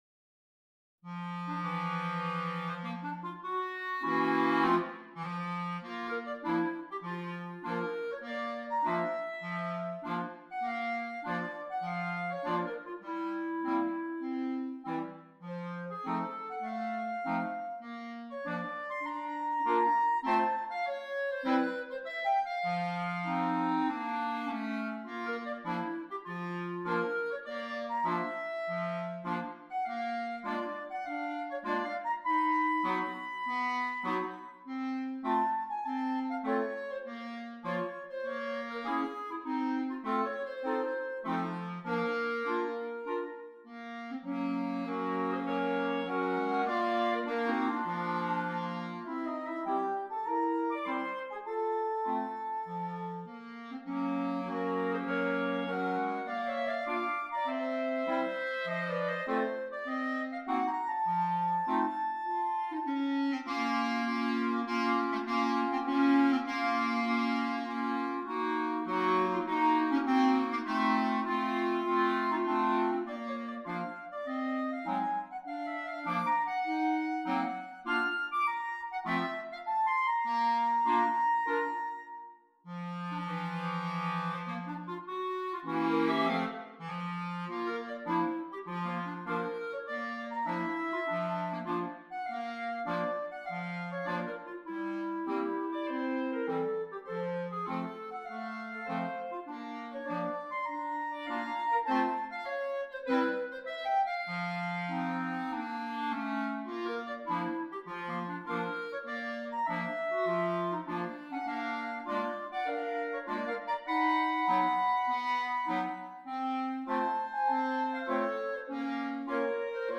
Soprano Clarinet, 4 Clarinets, Bass Clarinet